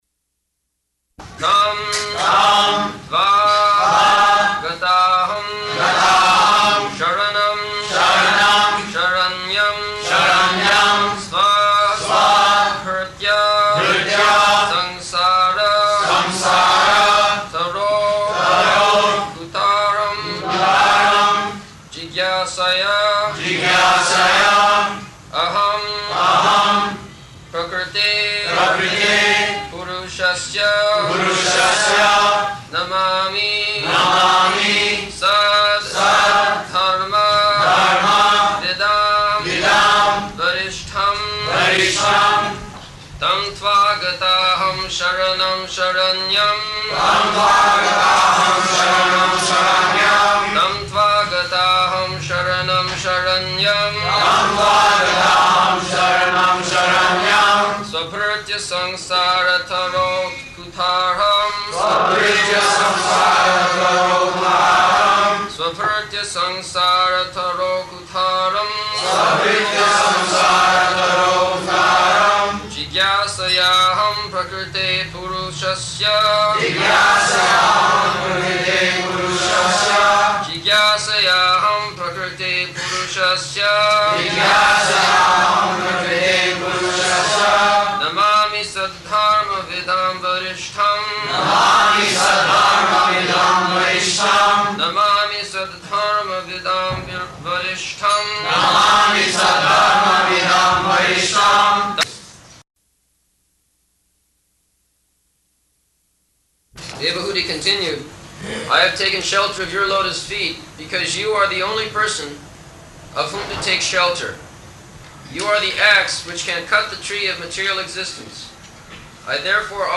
Location: Bombay
[noise of firecrackers in background throughout]
[devotees repeat]